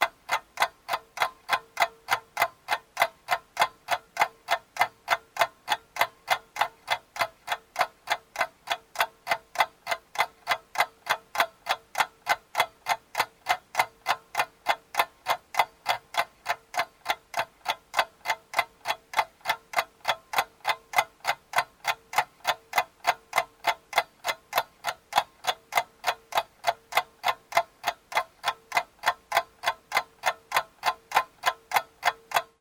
Clock.wav